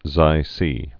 (zīsē, sī-, ksē-)